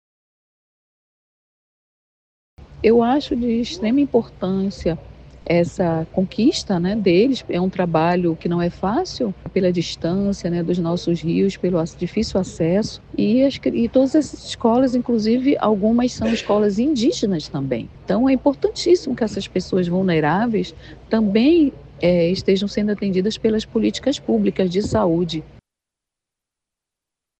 A procuradora de Justiça e coordenadora do projeto, Delisa Ferreira, destaca a importância da alta taxa de cobertura vacinal em escolas de áreas ribeirinhas.